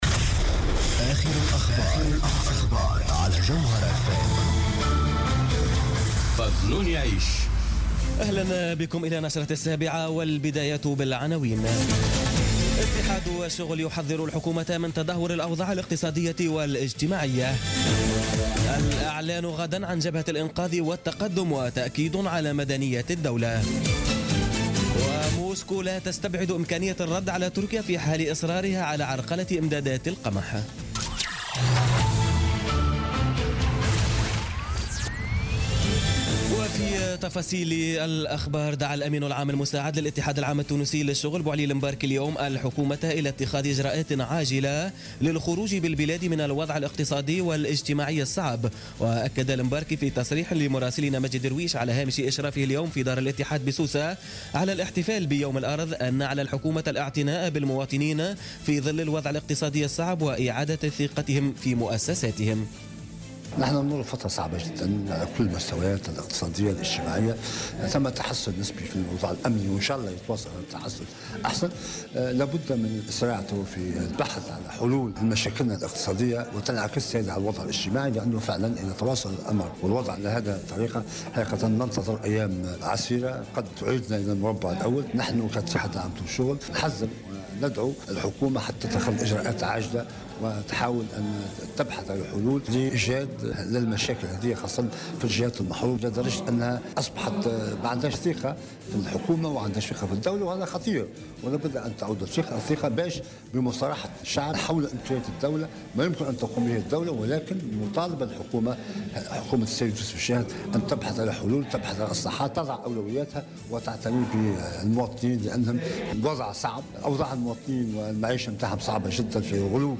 نشرة أخبارالسابعة مساء ليوم السبت غرة أفريل 2017